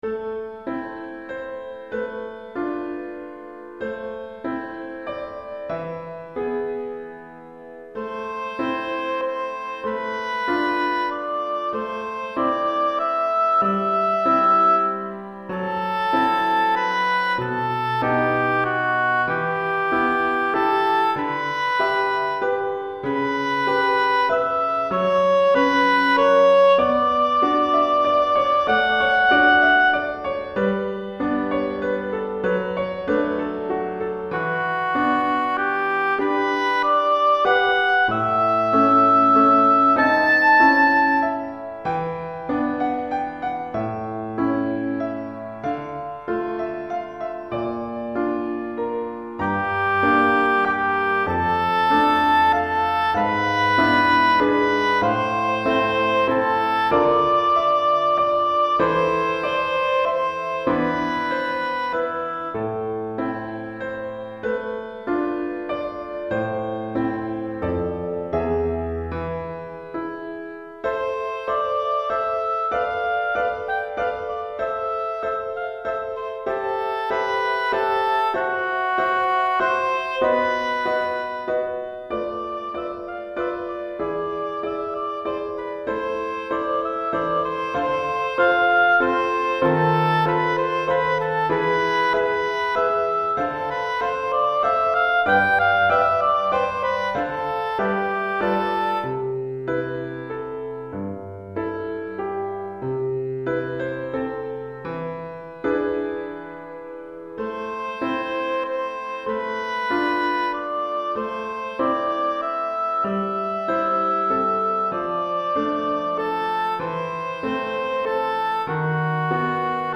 pour hautbois et piano DEGRE CYCLE 1 Durée